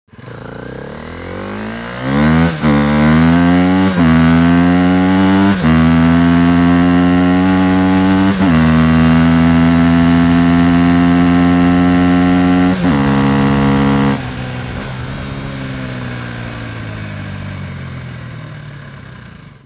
جلوه های صوتی
دانلود صدای موتور 8 از ساعد نیوز با لینک مستقیم و کیفیت بالا
برچسب: دانلود آهنگ های افکت صوتی حمل و نقل دانلود آلبوم صدای موتورسیکلت از افکت صوتی حمل و نقل